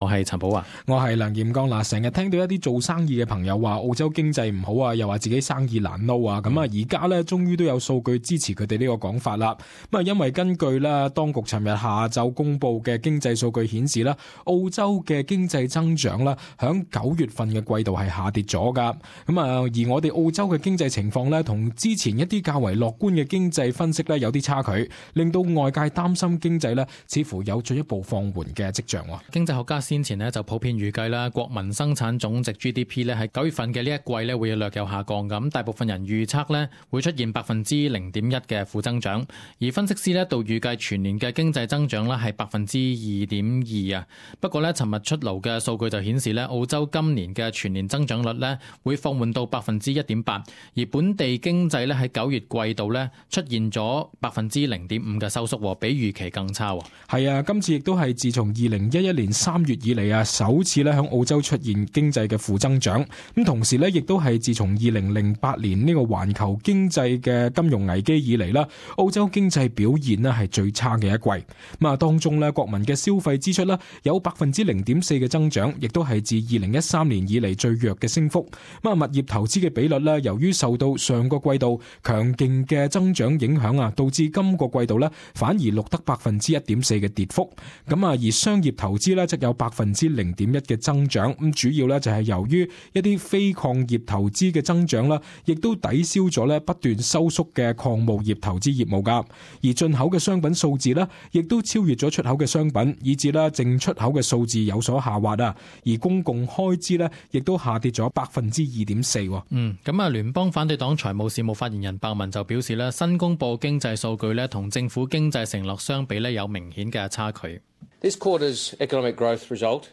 【時事報導】澳洲九月份經濟負增長 | 全球金融危機以來最差